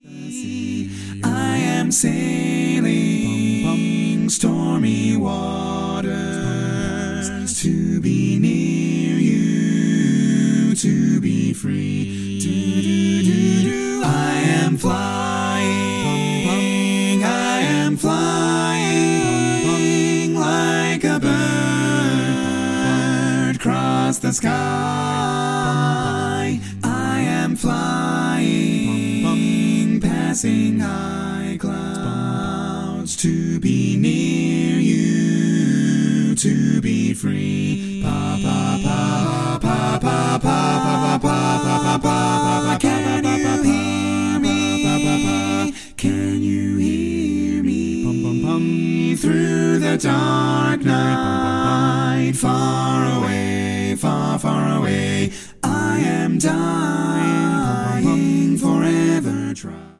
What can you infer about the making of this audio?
all/full mix